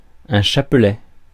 Ääntäminen
France: IPA: /ʃa.plɛ/